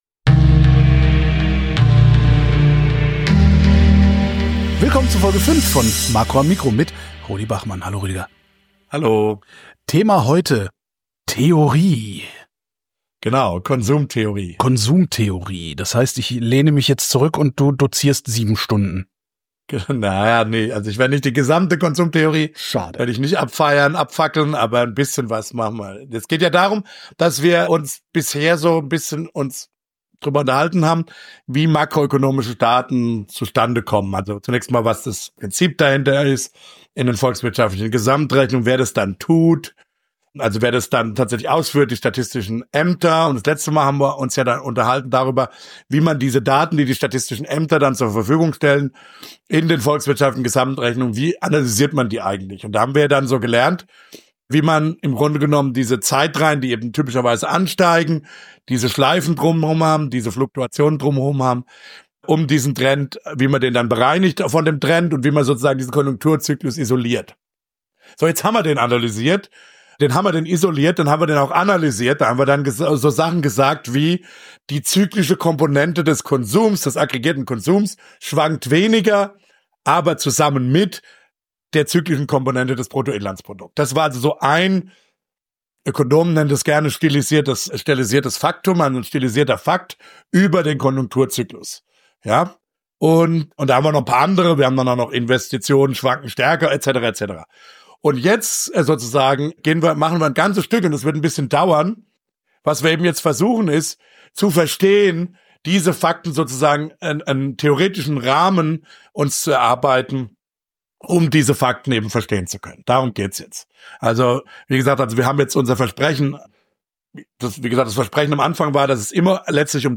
Weil in jeder Sendung geredet wird, habe ich mir erlaubt, das Projekt nach einem Satz aus Gottfried Benns Gedicht “Kommt” zu benennen.